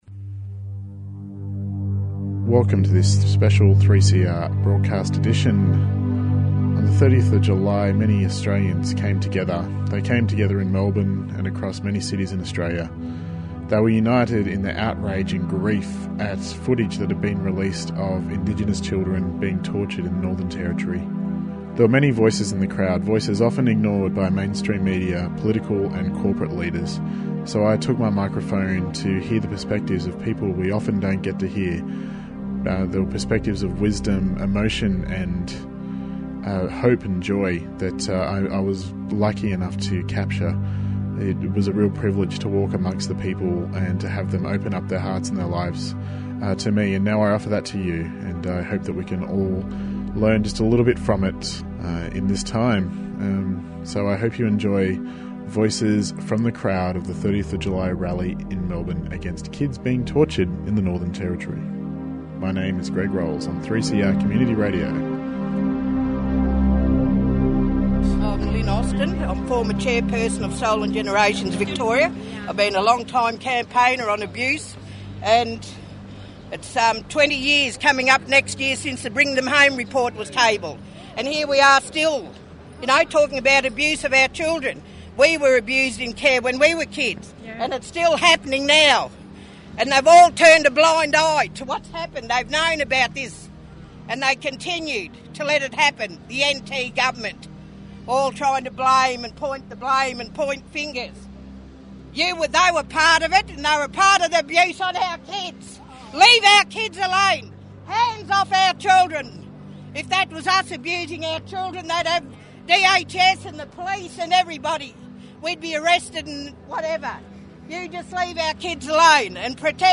with speakers and community members at the rally